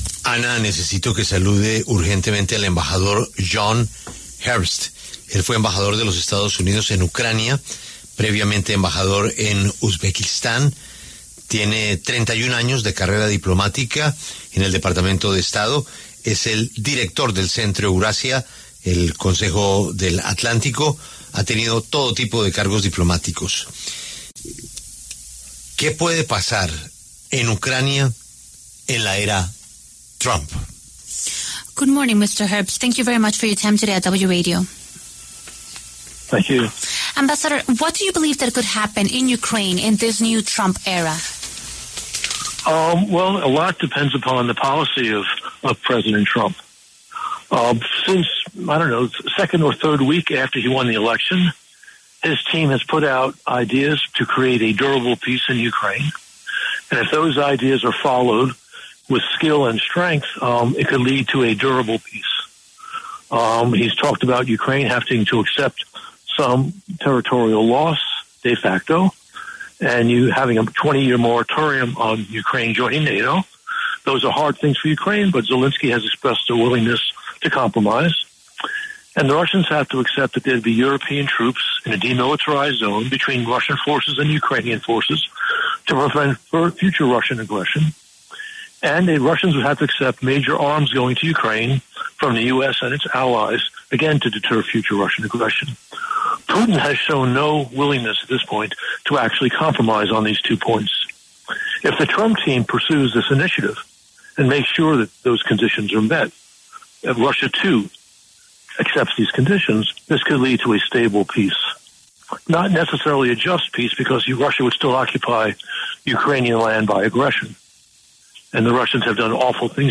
John Herbst, exembajador de Estados Unidos en Ucrania, analizó en La W lo que puede venir para la guerra con la influencia de Trump sobre Zelenski y Putin.